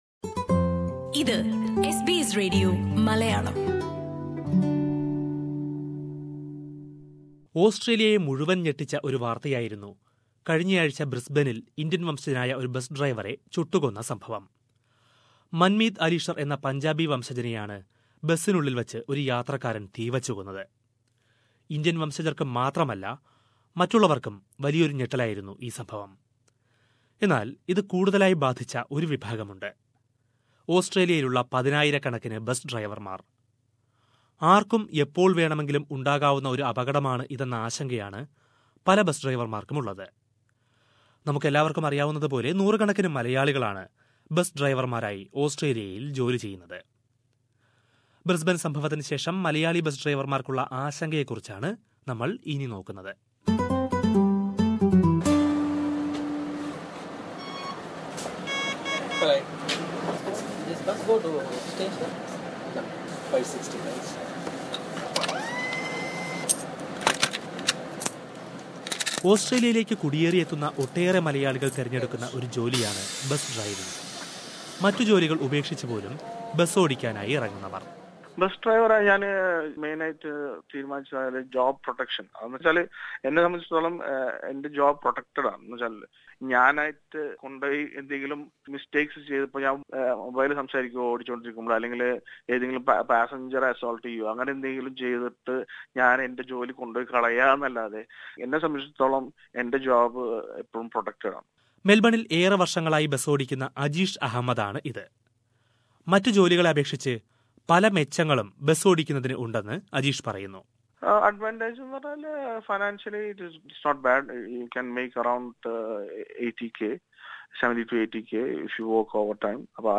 ചില മലയാളി ബസ് ഡ്രൈവർമാരോട് എസ് ബി എസ് മലയാളം സംസാരിച്ചു.